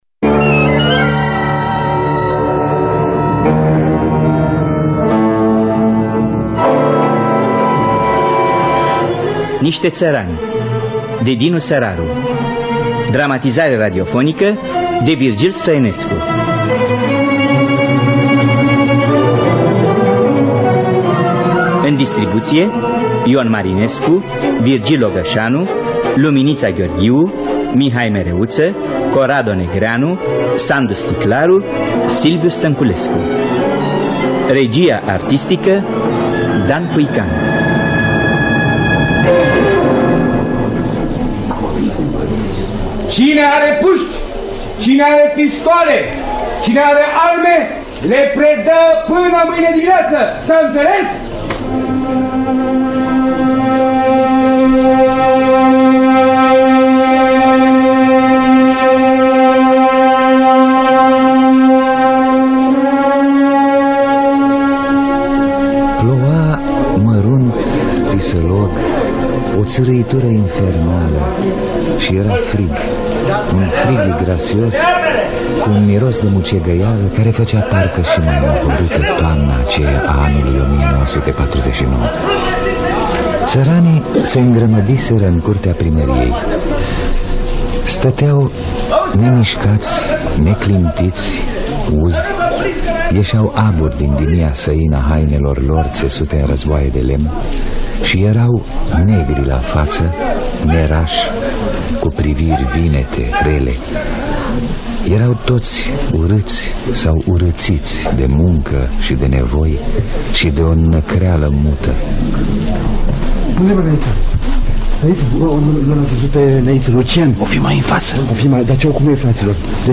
Niște țărani de Dinu Săraru – Teatru Radiofonic Online